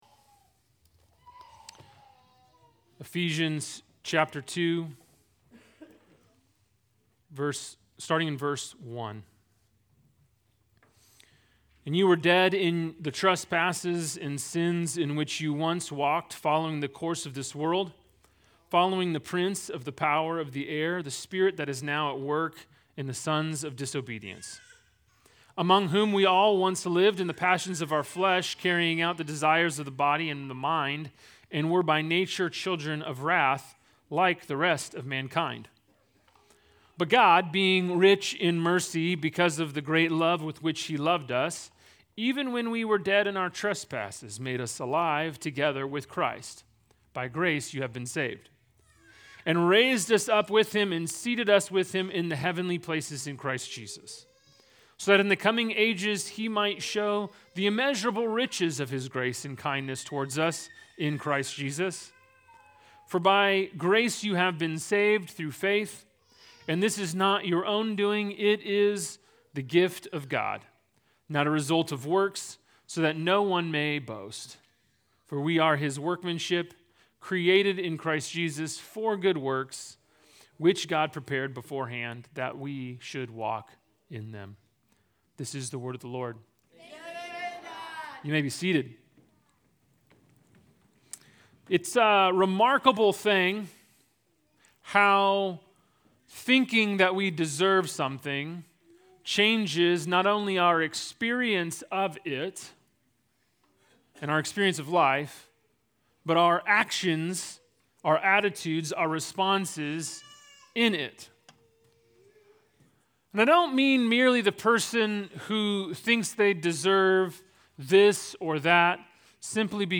Sermons from Proclaim Church